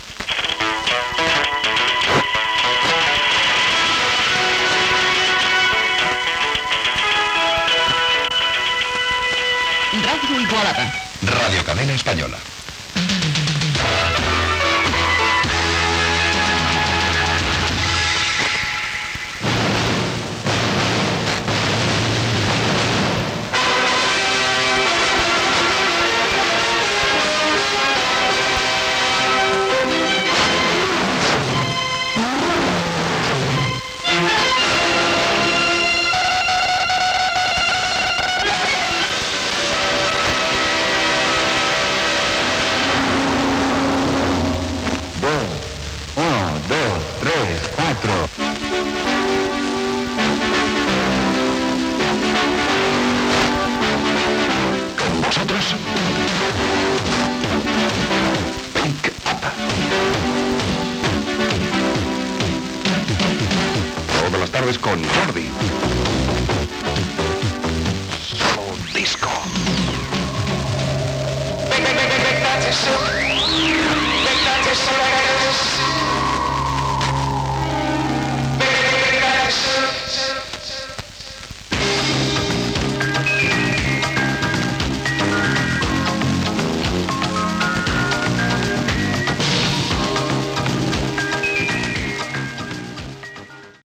Indicatiu i inici del programa.
Musical
FM